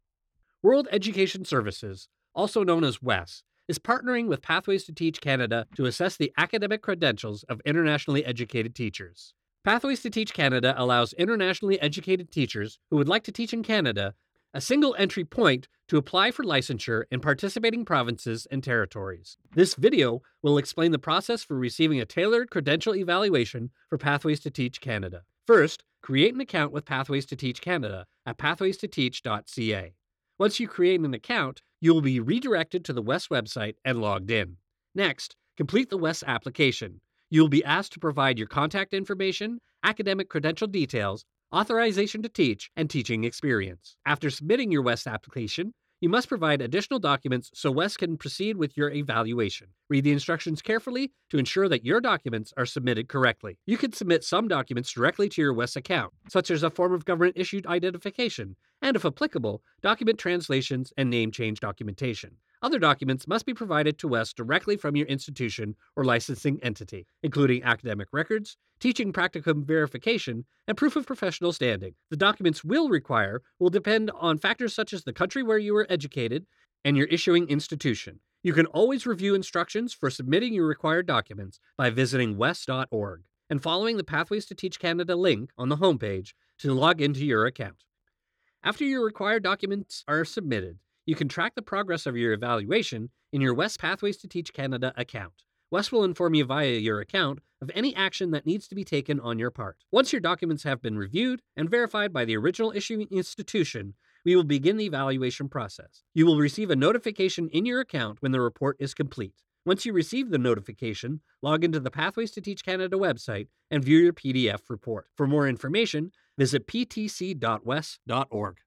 An Atlantic Canadian Voice Actor with lots of Commercial Experience!
eLearning
English (North American)
Young Adult
Middle Aged